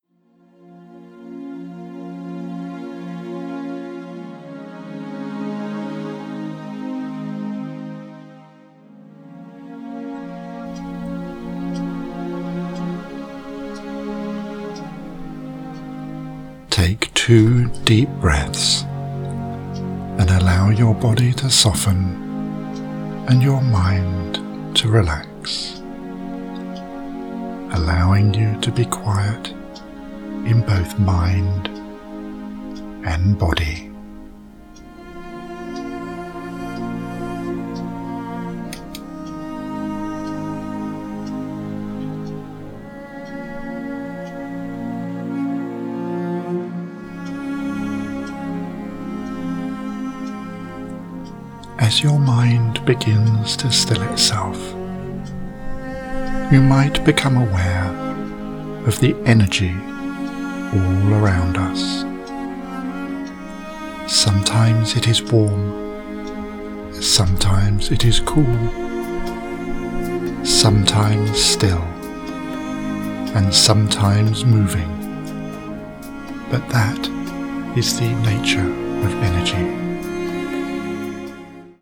Joy in your Heart is my second guided meditation CD, released in June 2016 and the follow up to Journey to Inner Peace.